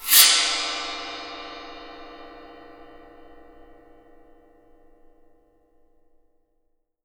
15 WIND GONG.wav